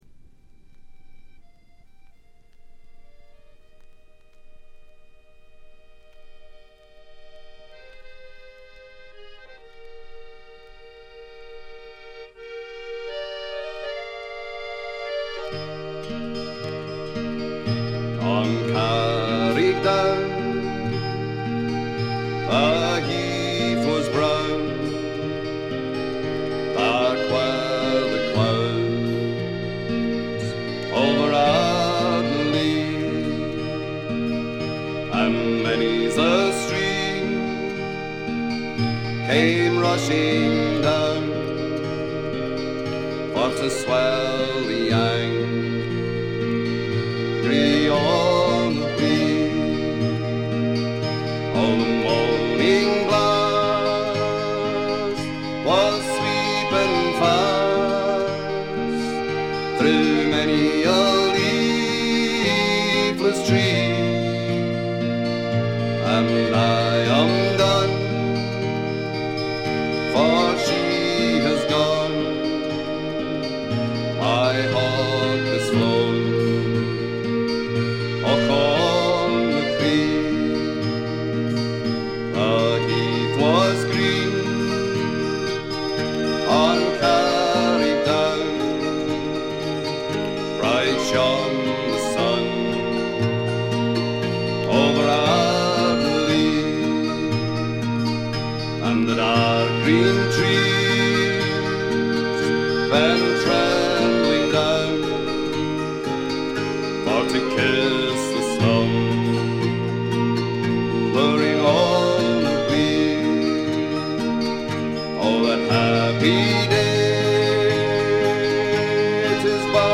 わずかなチリプチ程度。
スコットランドのトラッド・グループ
ギター、笛、アコーディオン、パイプ等が織りなす美しい桃源郷のような世界が展開されます。
試聴曲は現品からの取り込み音源です。
vocals, mandola, mandoline, whistle, guitar
vocals, banjo, guitar
accordion, Highland pipes, keyboards